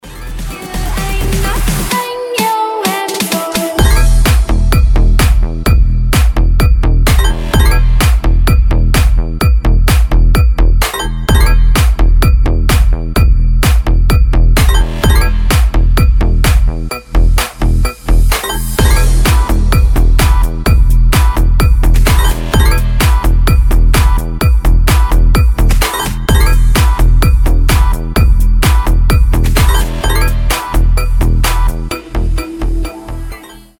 • Качество: 320, Stereo
мелодичные
Club House
future house
красивый женский голос
звонкие
ксилофон
ремиксы
Классный вьетнамский клубнячок